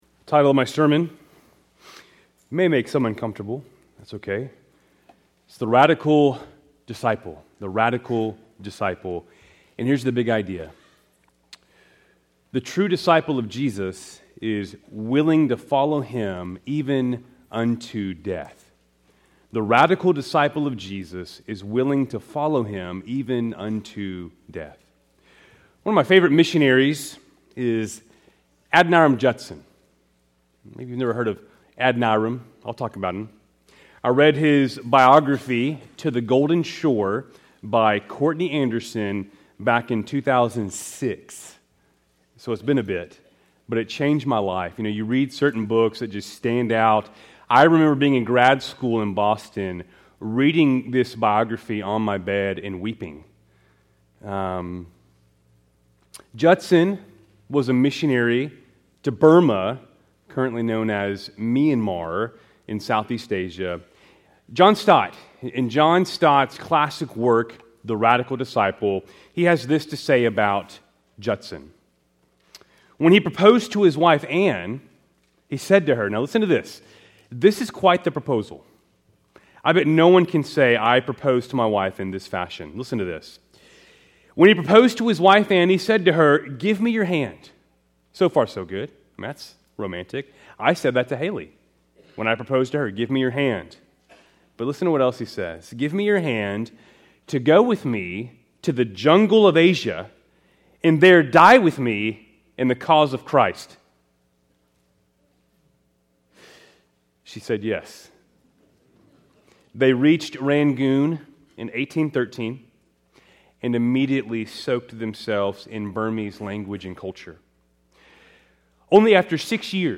Keltys Worship Service, February 23, 2025